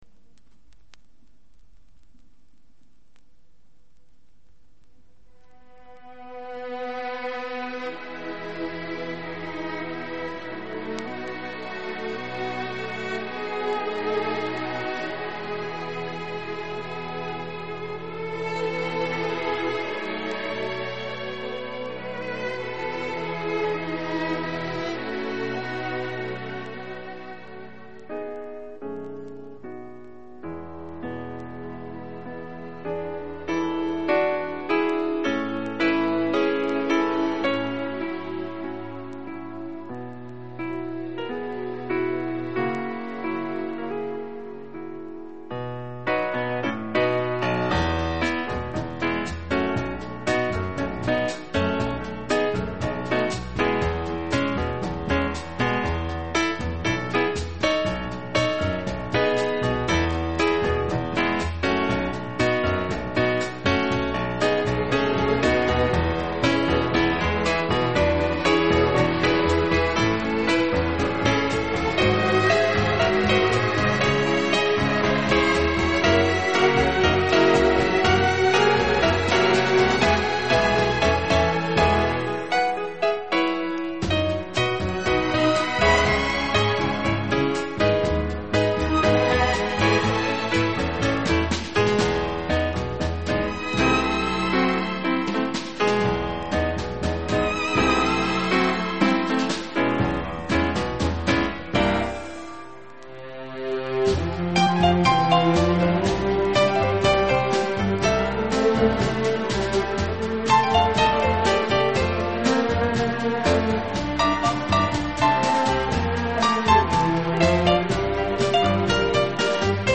Genre:Easy Listening
a swingy rhapsody